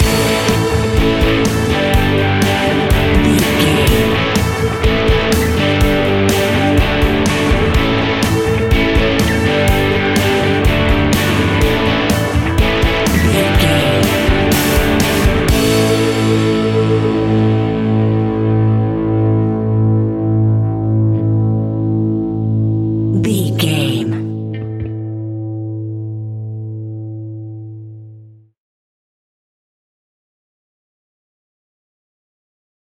Uplifting
Mixolydian
hard rock
blues rock
Rock Bass
heavy drums
distorted guitars
hammond organ